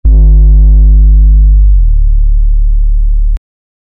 hello-bass-boom--r74cj3ra.wav